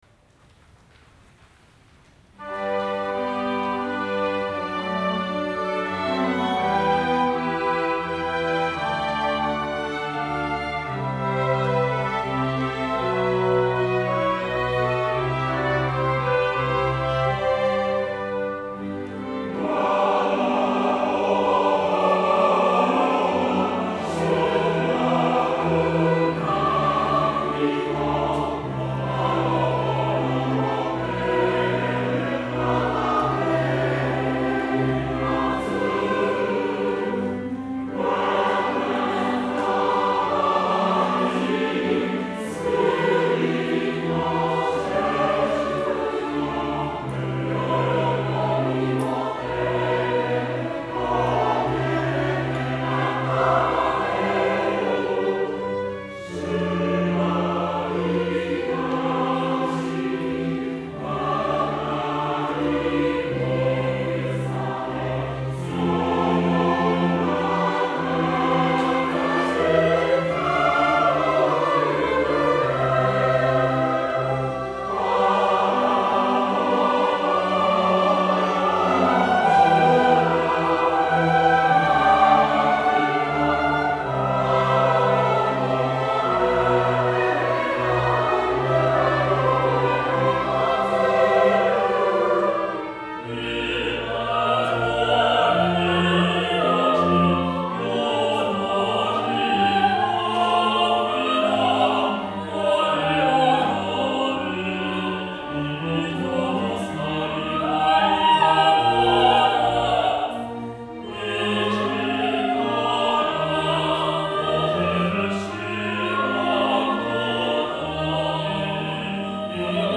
Midi Instrumental ensemble (Fl-Fl-Cl-Fg-St_S-Hr_Fl-Ob-Eh-Fg-Str)